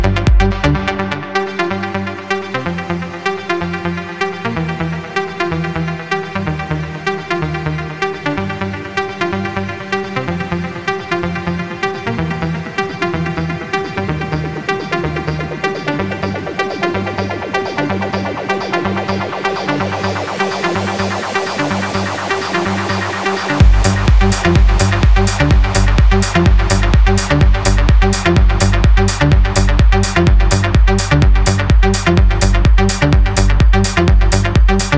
2023-07-28 Жанр: Танцевальные Длительность